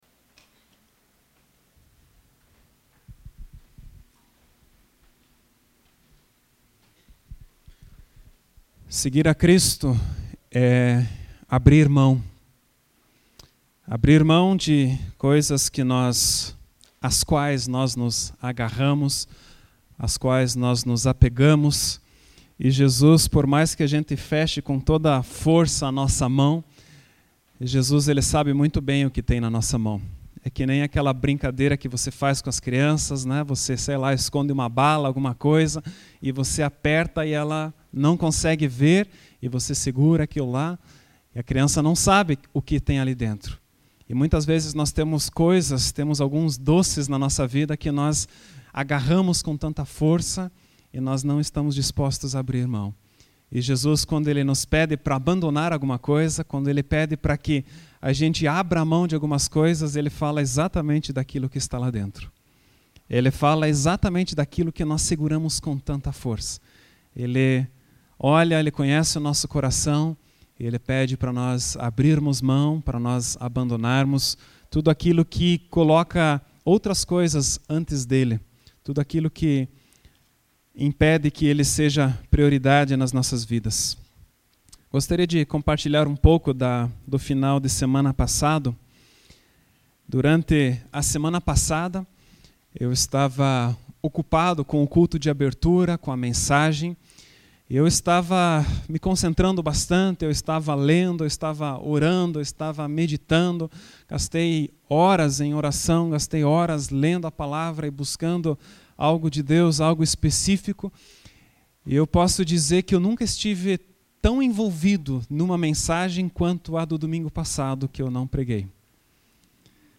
Essa é a palavra do segundo culto, que estamos chamando de EPISÓDIO 2 do AMISTAD.